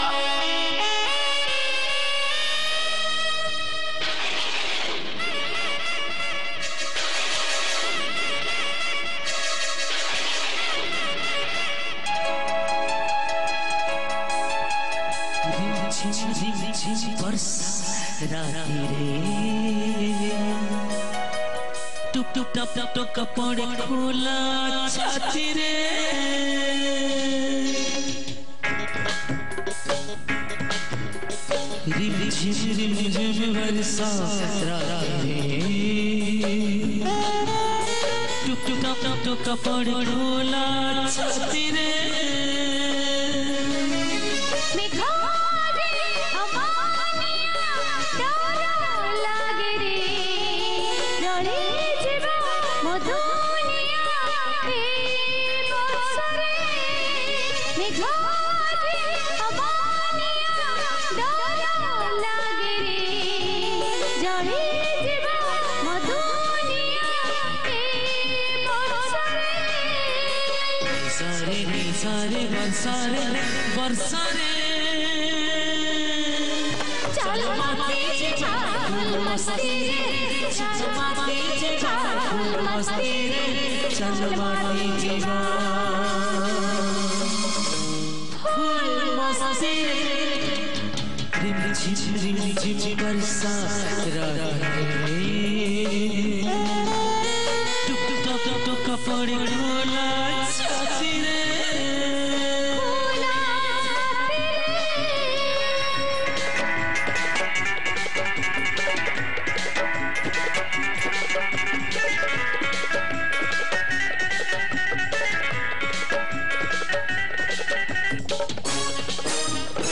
Odia Jatra Song